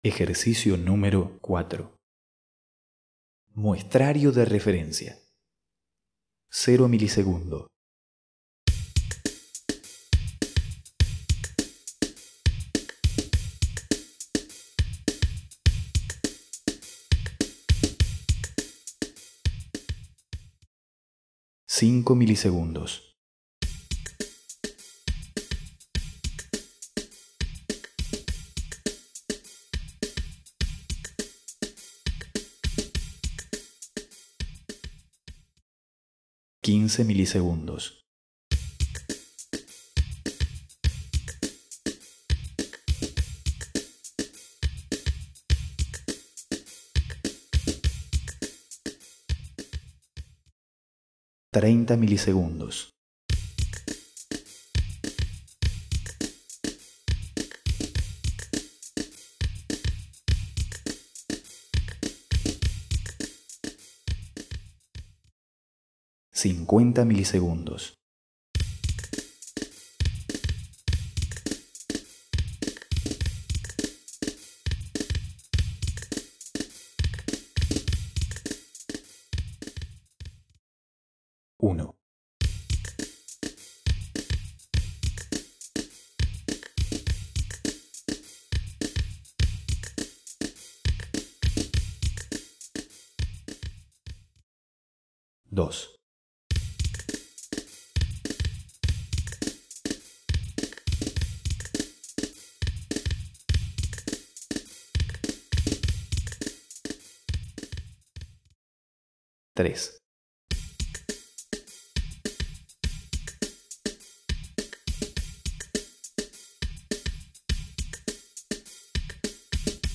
2) Reconocimiento de 4 tiempos de delay (retardos) Los canales izquierdo y derecho se van retrasando produciendo un efecto particular.
(5 ms; 15ms; 30ms; 50ms)
Delay time - Size 2.372 kb (file name ej01dly.mp3)